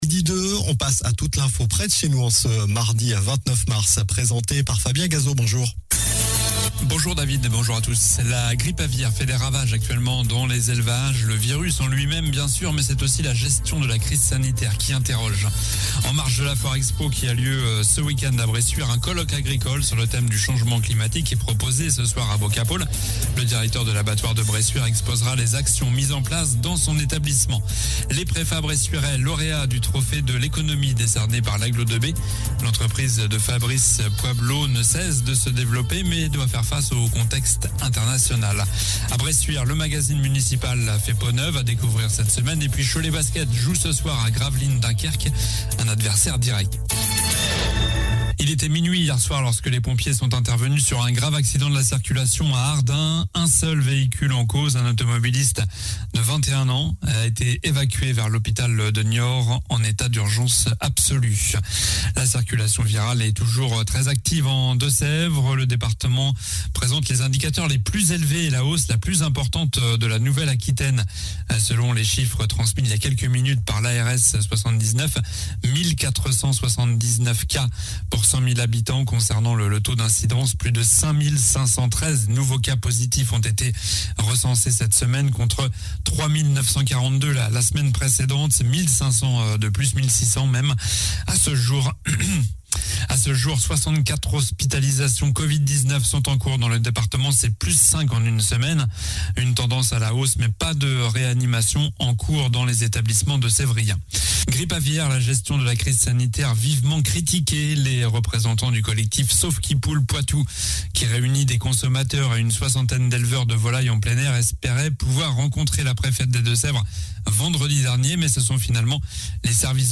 Journal du mardi 29 mars (midi)